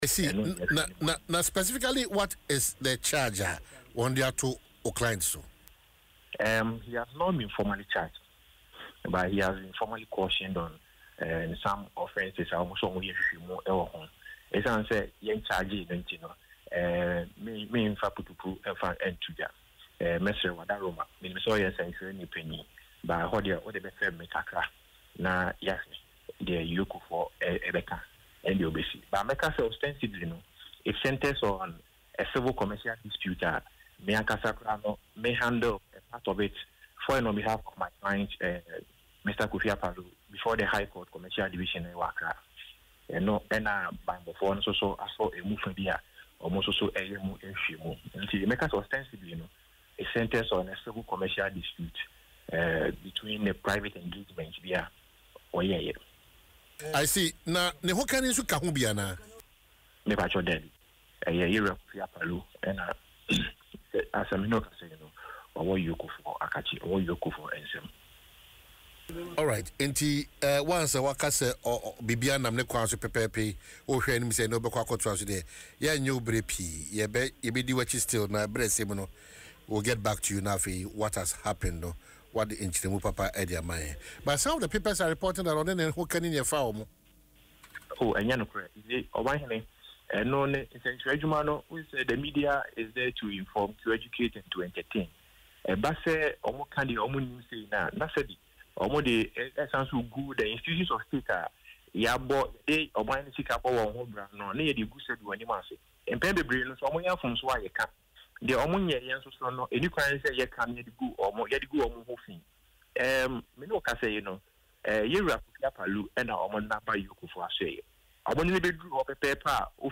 Speaking on Adom FM’s Dwaso Nsem